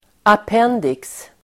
Uttal: [ap'en:diks]